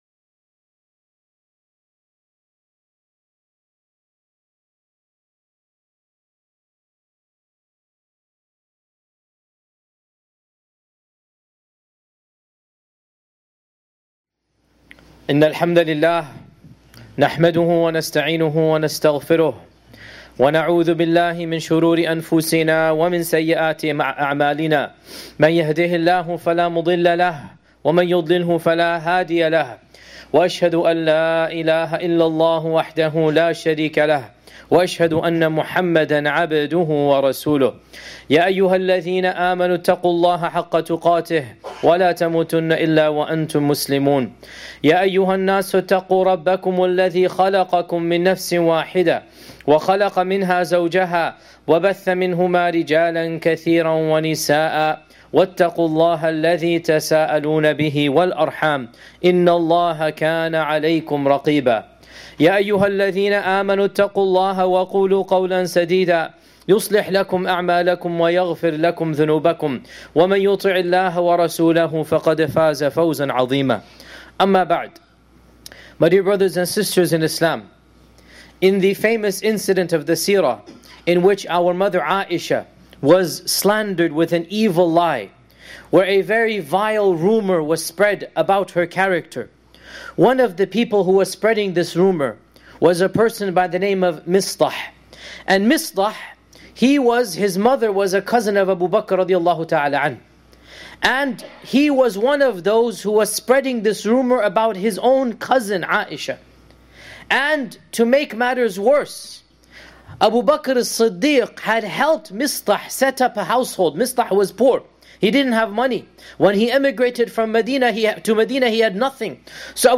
This is a Khutbah and topic which impacts every single human being dealing with strangers along with loved ones (such as Parents, Spouses, Siblings and children) almost daily.